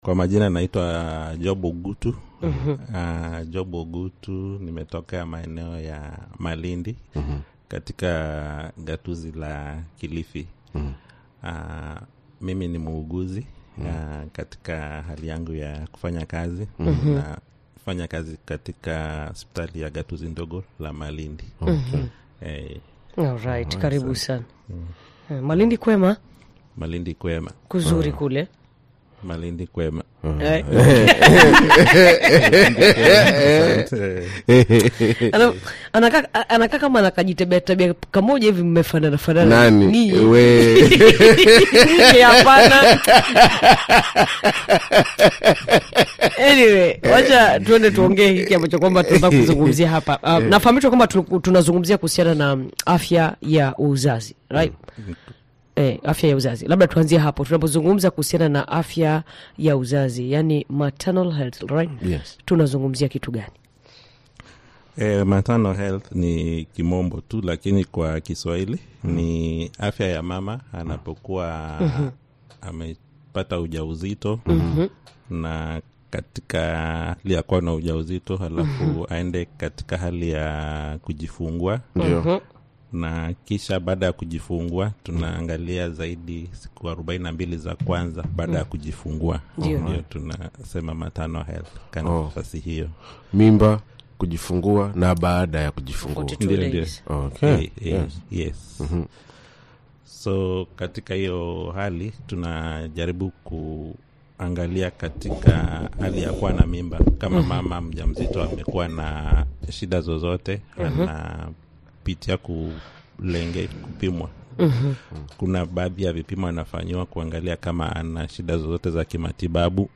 Episode five of our UZIMA-DS radio show series highlights maternal health, one of the focus areas of our Maternal, Newborn and Child Health project.